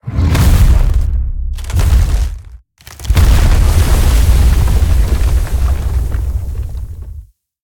Sfx_creature_iceworm_spearattack_stuck_01.ogg